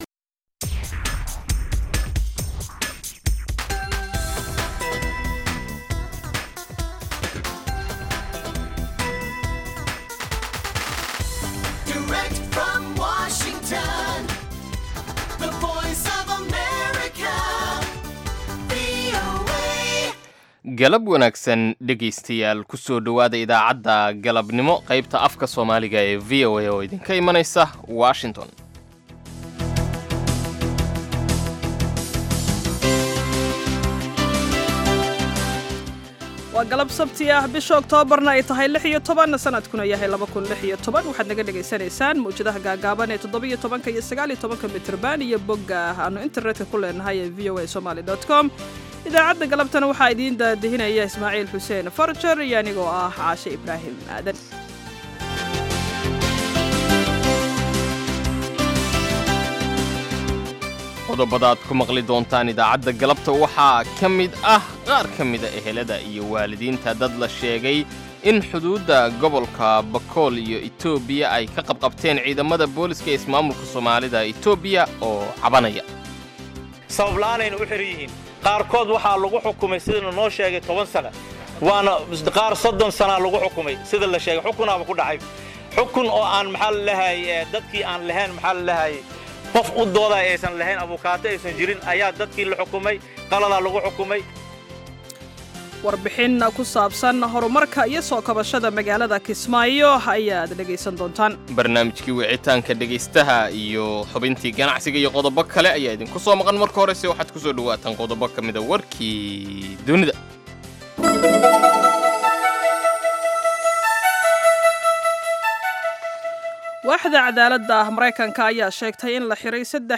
Idaacadda Galabnimo waxaad ku maqashaan wararka ugu danbeeya ee caalamka, barnaamijyo, ciyaaro, wareysiyo iyo waliba heeso.